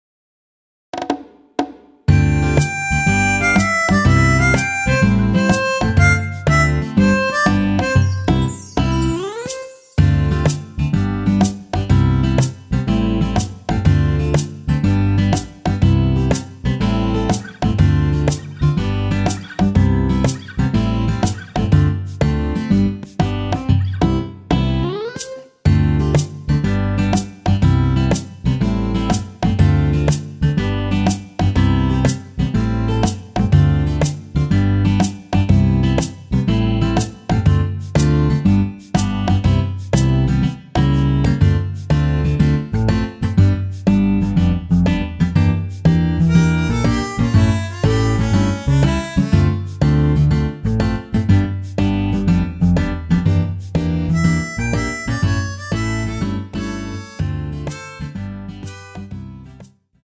원키에서(+1)올린 MR입니다.
앞부분30초, 뒷부분30초씩 편집해서 올려 드리고 있습니다.